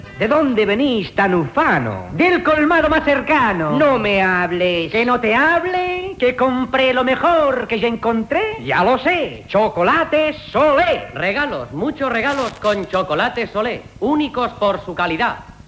Anunci de Chocolates Solé
Extret de Crònica Sentimental de Ràdio Barcelona emesa el dia 15 d'octubre de 1994.